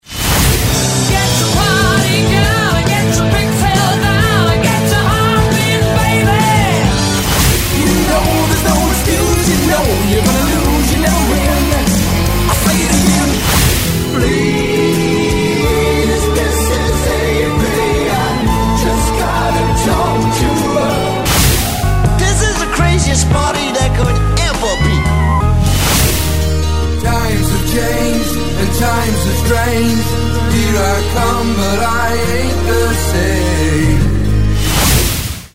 Five iconic songs — all mashed together — each with Mom, Mama, Mother, or Mommy in the lyrics.